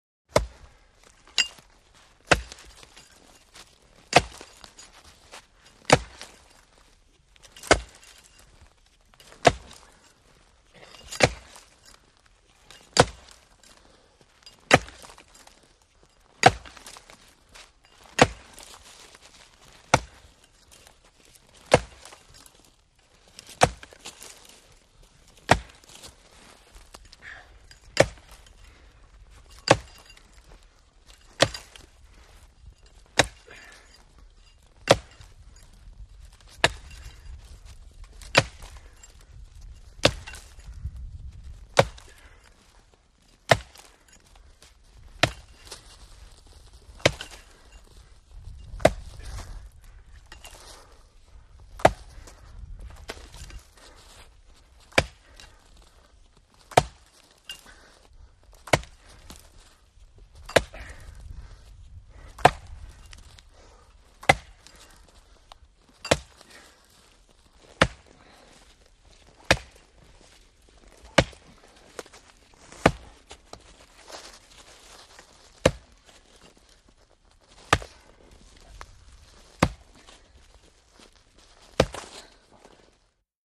Logging|Exterior
IMPACTS & CRASHES - WOOD LOGGING: EXT: Chopping trees with axe, multiple impacts, some movement between hits.